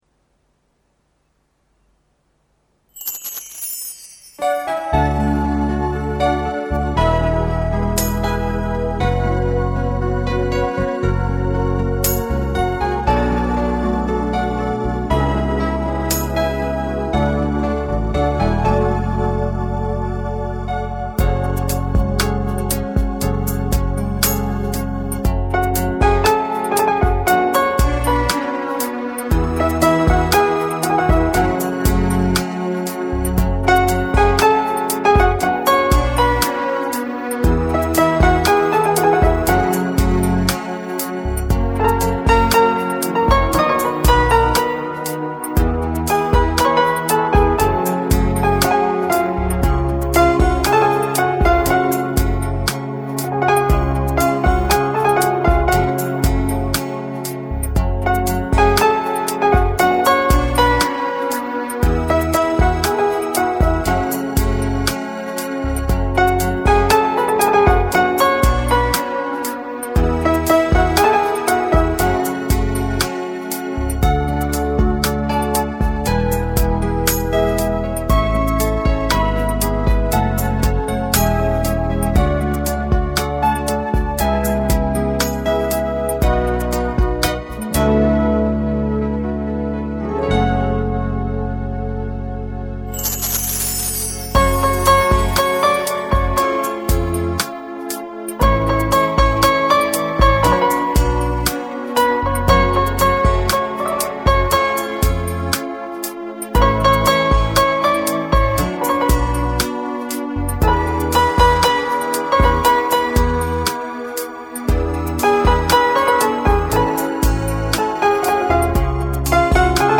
Instrumental Songs